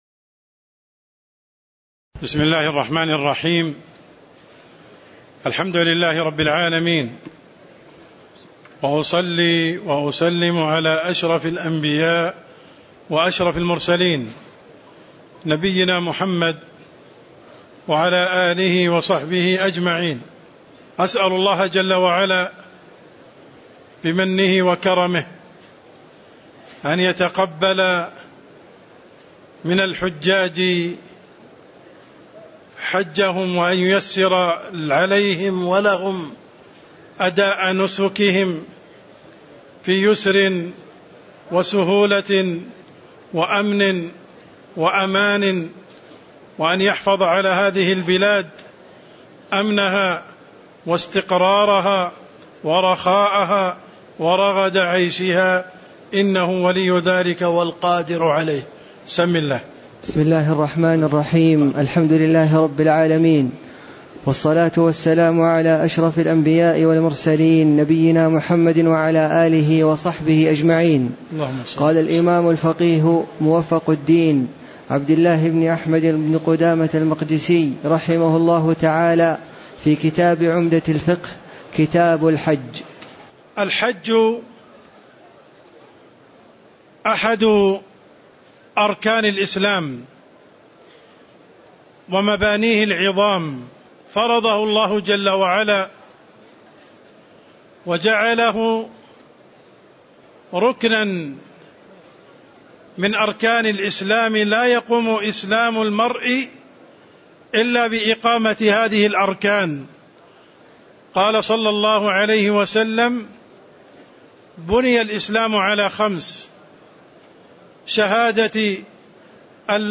تاريخ النشر ٢٥ ذو القعدة ١٤٣٩ هـ المكان: المسجد النبوي الشيخ: عبدالرحمن السند عبدالرحمن السند قوله: يجب الحج والعمرة في العمر مرّة (01)